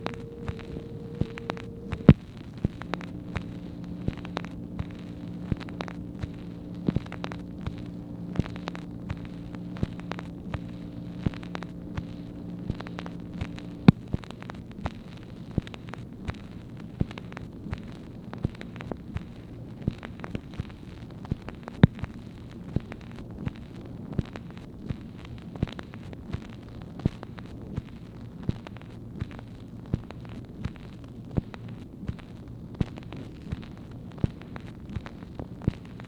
MACHINE NOISE, April 28, 1964
Secret White House Tapes | Lyndon B. Johnson Presidency